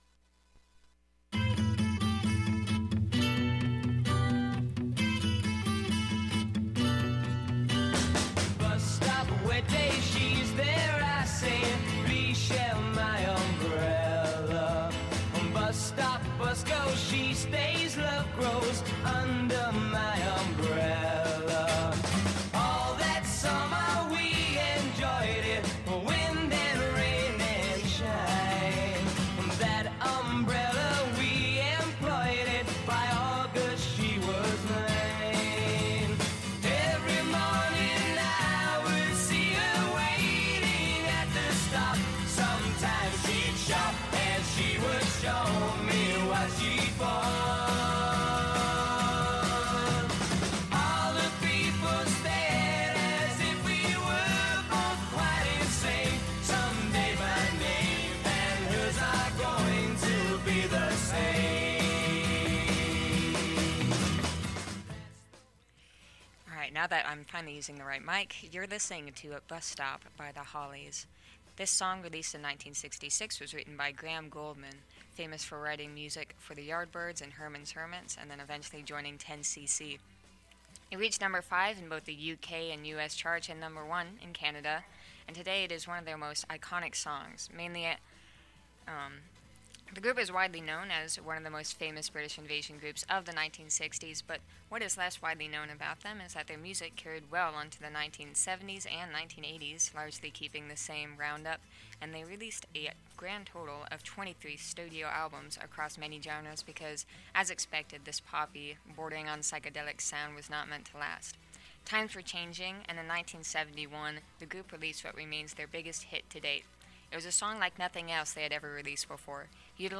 Thank you everyone who tuned in tonight and excused the slightly less polished nature of this broadcast… This was our first Record Deep Dive, and I decided to experiment with many things including the lack of a script or background music!